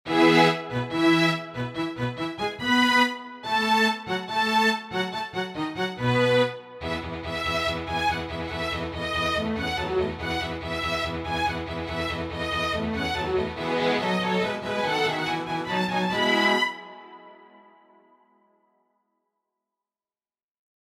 原曲はGメジャーですが、こちらを移調してみます。
Eメジャーに下げてみました。
パッと明るい感じの原曲に比べて、ちょっと落ち着いたように聴こえませんか？